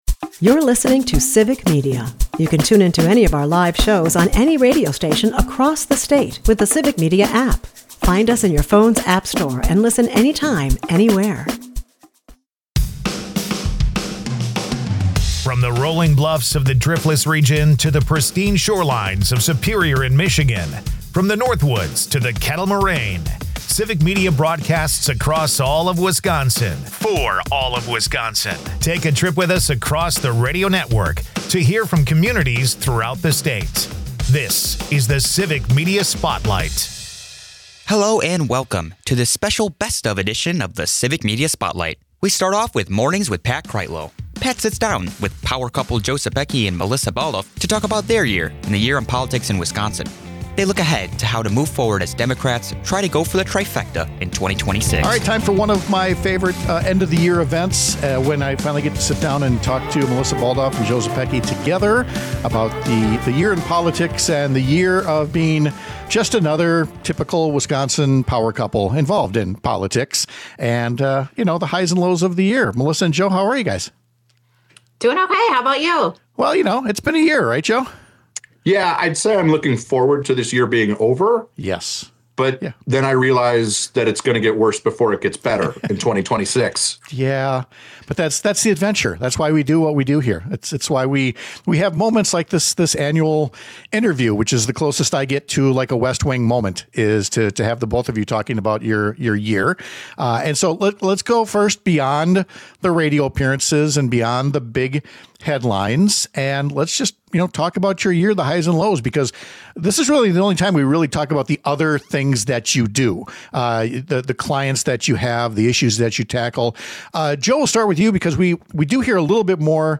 Hello and welcome to this best of edition of the Civic Media Spotlight!